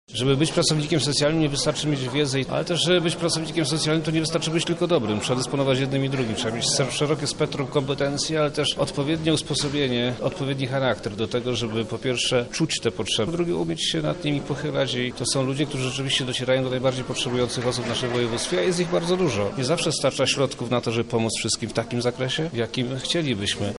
O tym jaki powinien być dobry pracownik socjalny mówi Przemysław Czarnek, wojewoda lubelski